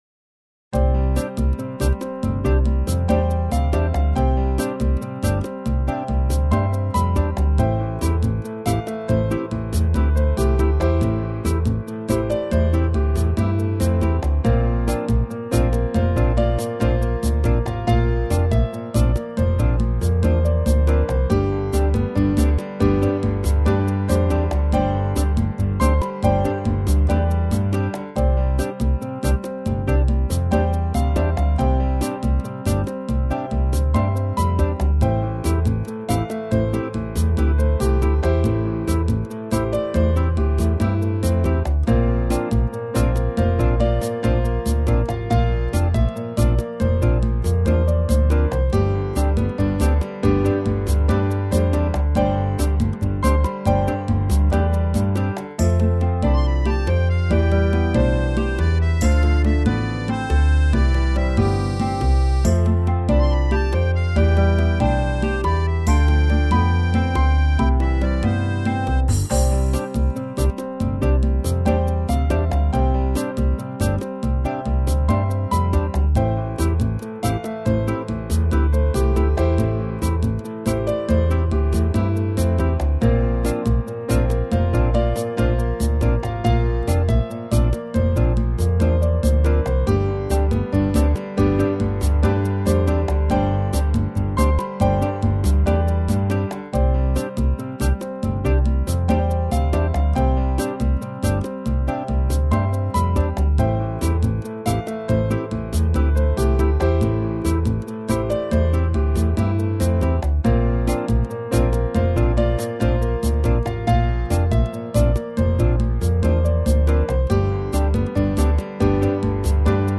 最初はバイオリンの音とか考えたのですが、やっぱ音源的にも好み的にもピアノ曲になりました。トークのBGMとか日常シーンとか使えそうなとこで使ってみてください。